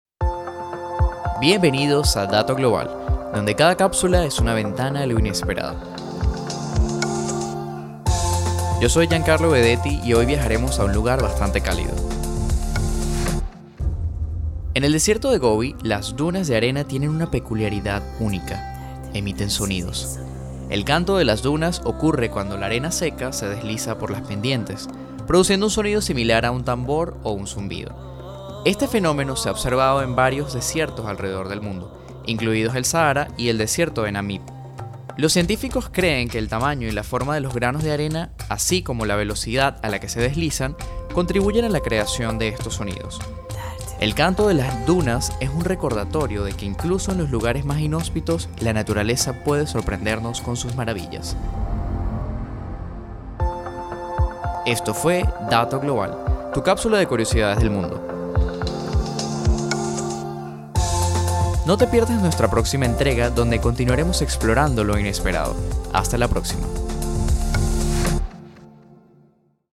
Estudiantes de la Universidad de Margarita